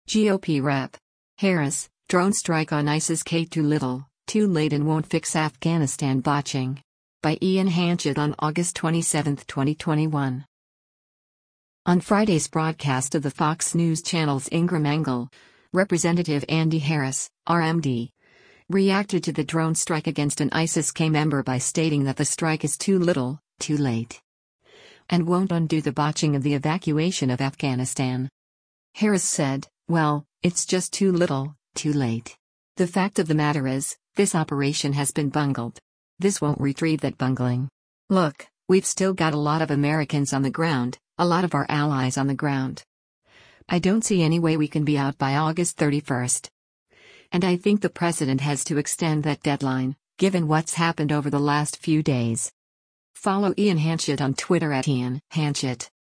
On Friday’s broadcast of the Fox News Channel’s “Ingraham Angle,” Rep. Andy Harris (R-MD) reacted to the drone strike against an ISIS-K member by stating that the strike is “too little, too late.”